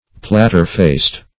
Platter-faced \Plat"ter-faced`\, a.
platter-faced.mp3